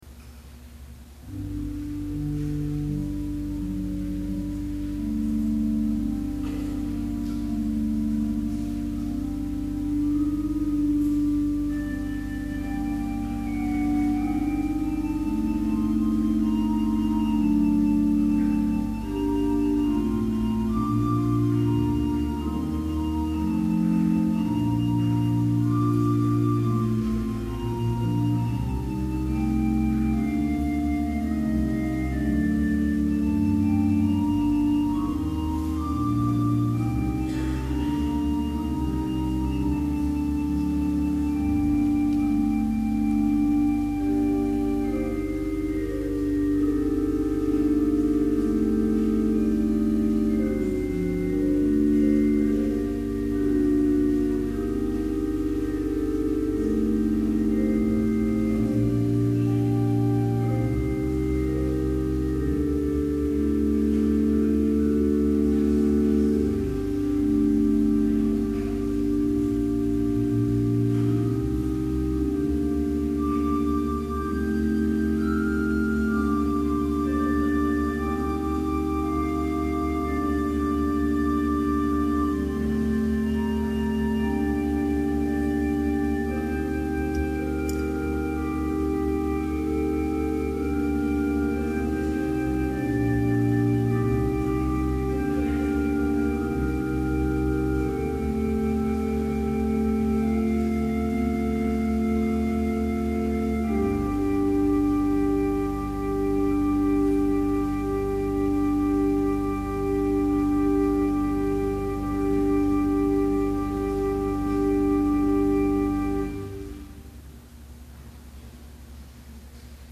Complete service audio for Summer Chapel - August 3, 2011